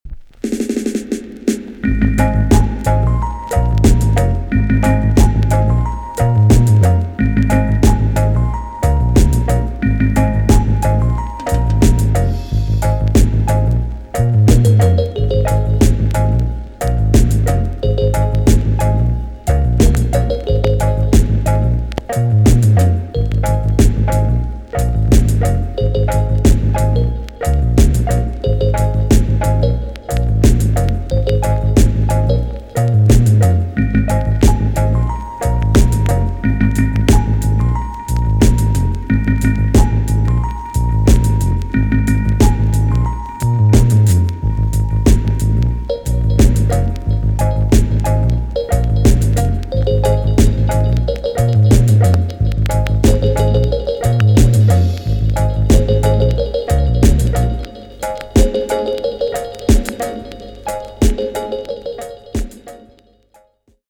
B.SIDE Version
EX-~VG+ 少し軽いチリノイズが入りますがキレイです。